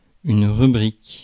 Ääntäminen
Synonyymit chapitre Ääntäminen France: IPA: [ʁy.bʁik] Haettu sana löytyi näillä lähdekielillä: ranska Käännöksiä ei löytynyt valitulle kohdekielelle.